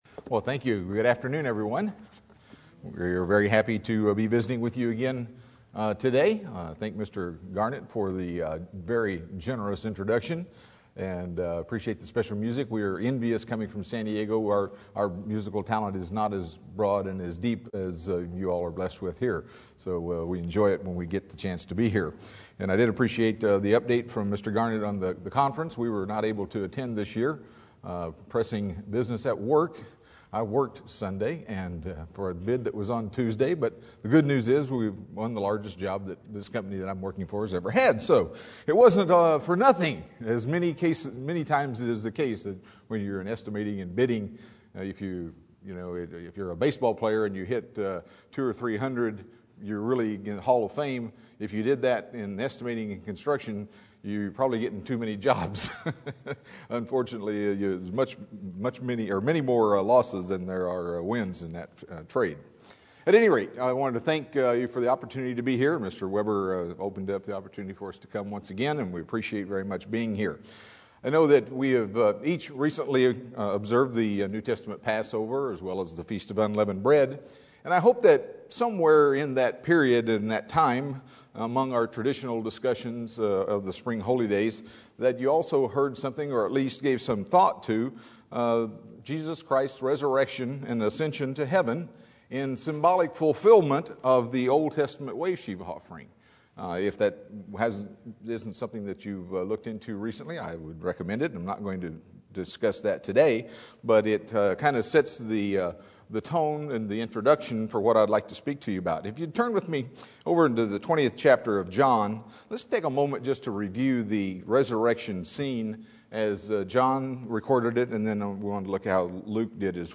UCG Sermon Studying the bible?
Given in Los Angeles, CA